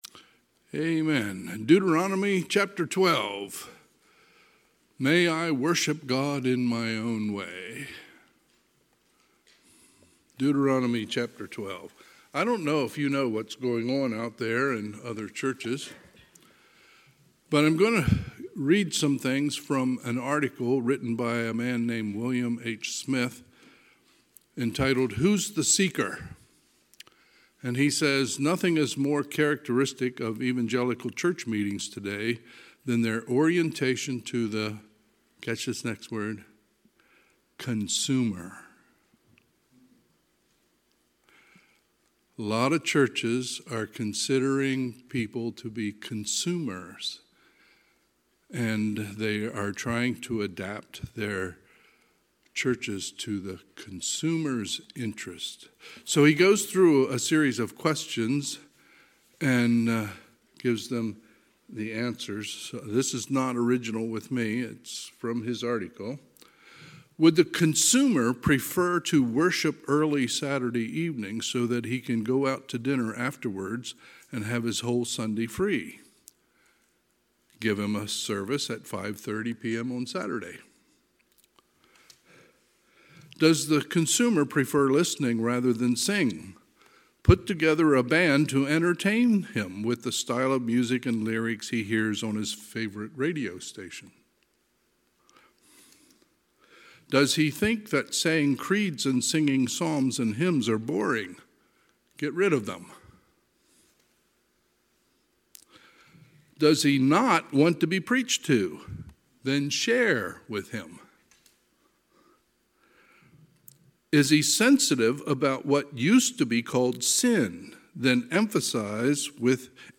Sunday, March 29, 2026 – Sunday PM